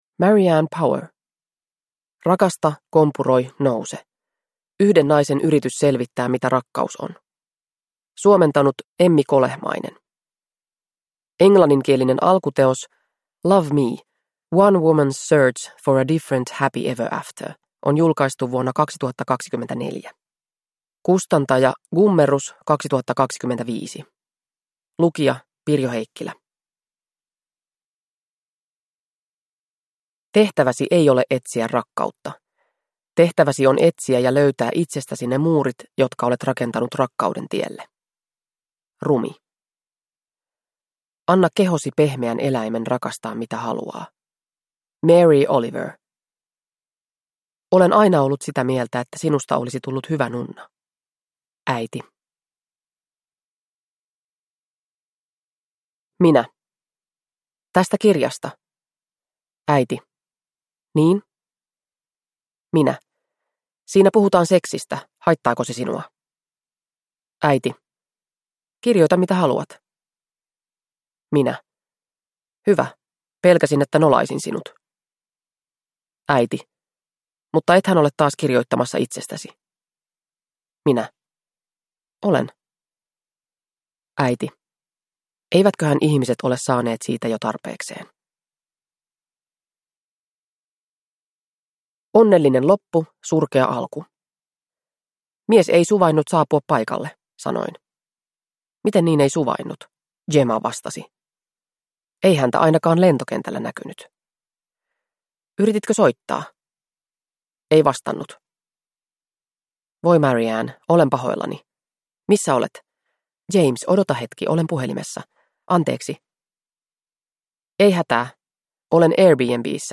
Rakasta, kompuroi, nouse – Ljudbok